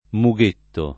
mughetto [ mu g% tto ] s. m.